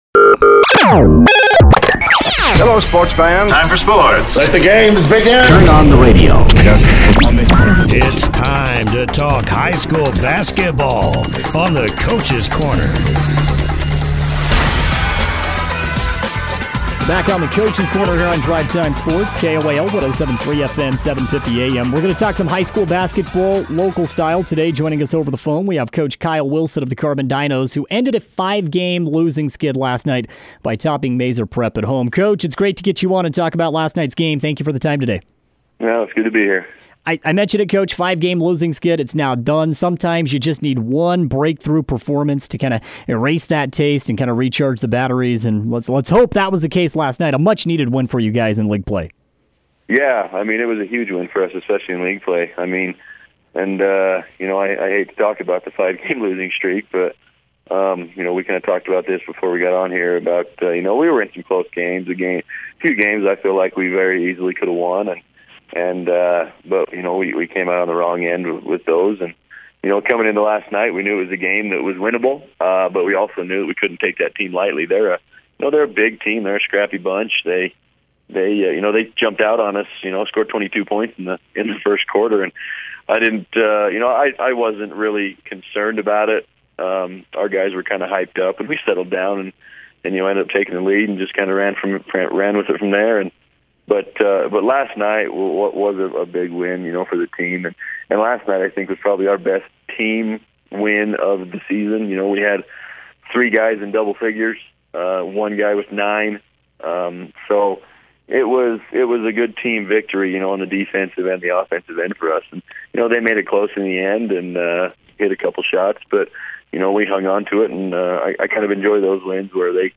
was a guest on KOAL’s Drive Time Sports